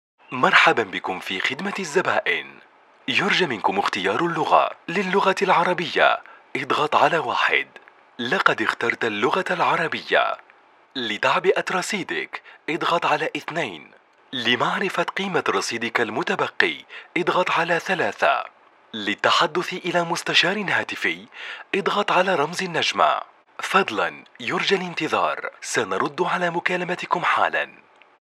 Voix Off Arabe Comédien Chanteur Narrateur Animateur Radio Tv Team Building
Sprechprobe: Sonstiges (Muttersprache):